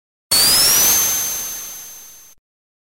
Звуки вращения
На этой странице собраны разнообразные звуки вращения: от легкого шелеста крутящихся лопастей до мощного гула промышленных механизмов.
2. Вращательный эффект